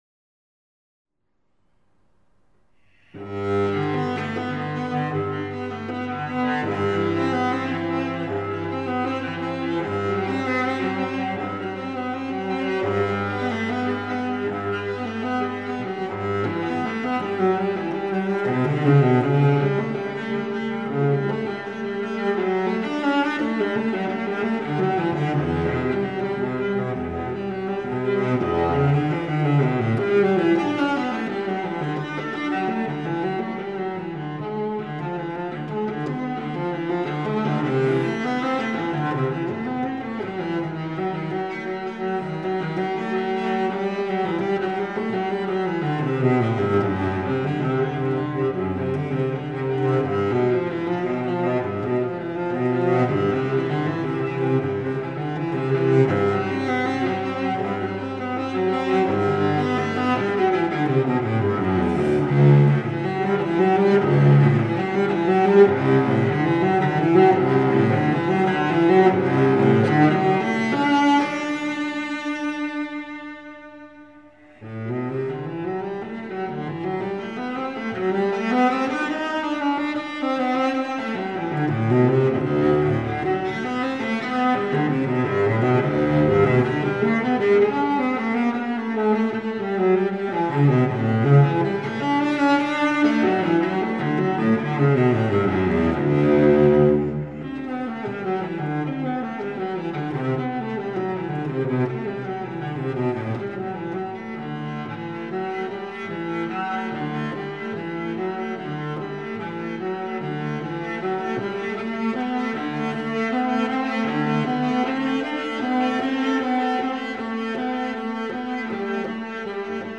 音乐类型：古典音乐
第一号组曲，Ｇ大调，BWV 1007